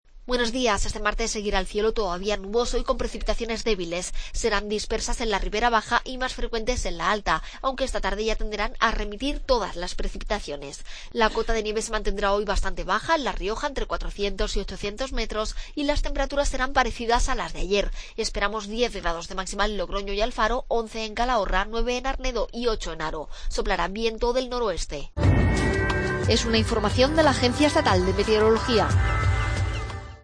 AUDIO: Pronóstico. Agencia Estatgal de Meteorología.